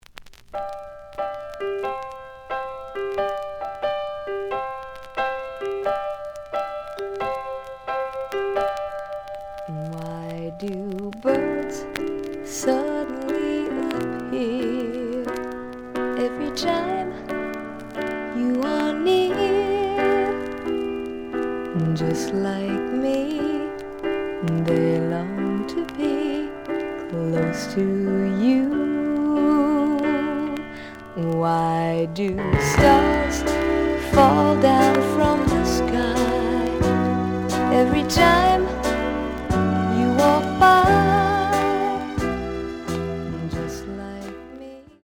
The audio sample is recorded from the actual item.
●Genre: Rock / Pop
B side plays good.)